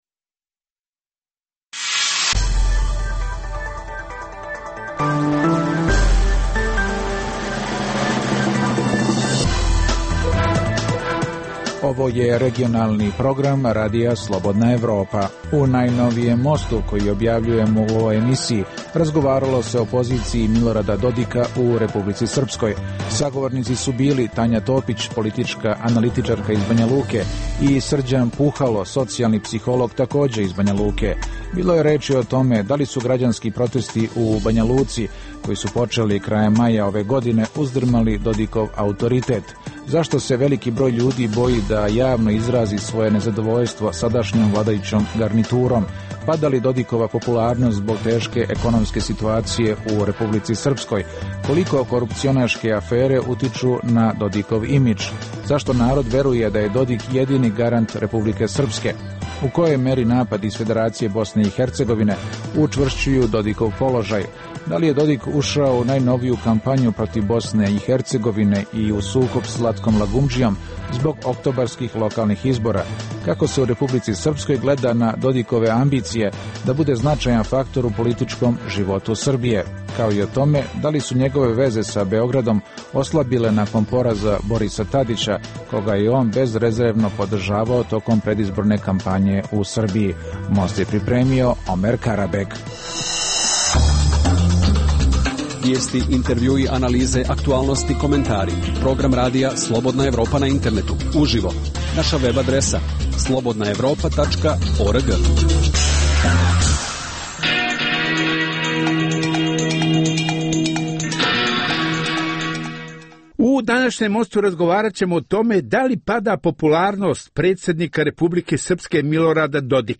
Dijaloška emisija o politici